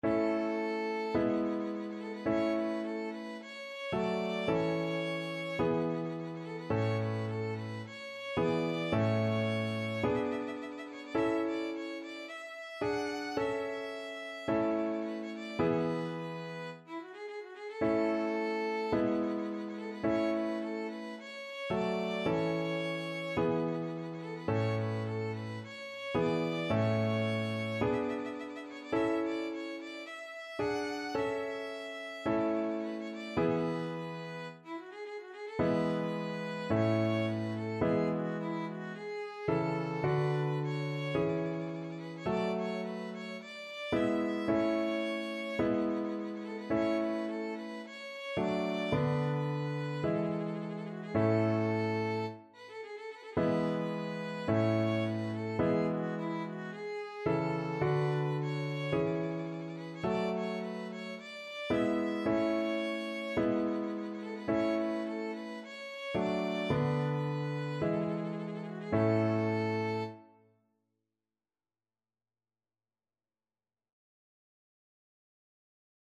Violin
A major (Sounding Pitch) (View more A major Music for Violin )
4/4 (View more 4/4 Music)
Classical (View more Classical Violin Music)